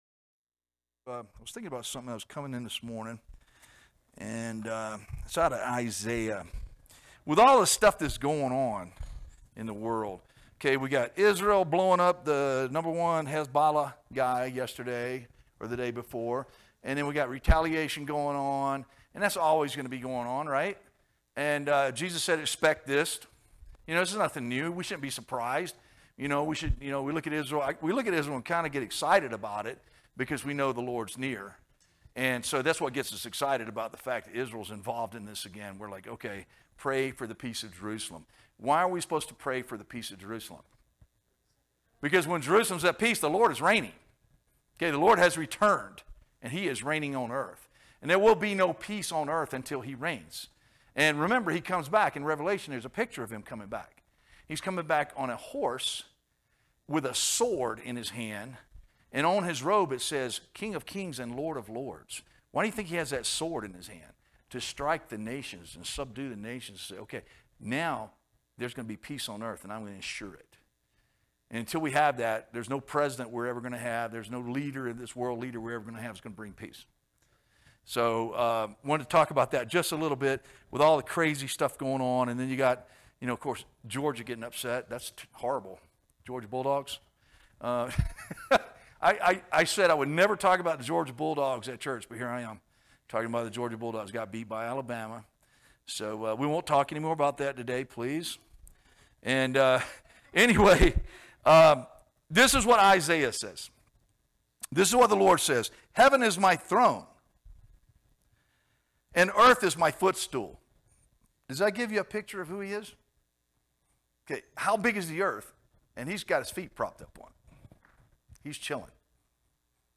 teaches a lesson from the Book of Hebrews, Chapter2